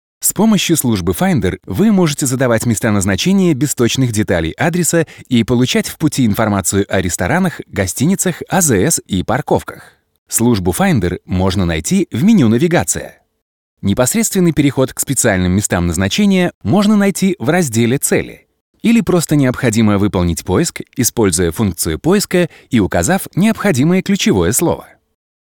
Native russian voiceover artist, actor and narrator.
Sprechprobe: eLearning (Muttersprache):